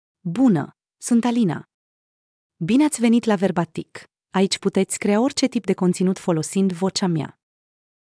Alina — Female Romanian (Romania) AI Voice | TTS, Voice Cloning & Video | Verbatik AI
Alina is a female AI voice for Romanian (Romania).
Voice sample
Listen to Alina's female Romanian voice.
Alina delivers clear pronunciation with authentic Romania Romanian intonation, making your content sound professionally produced.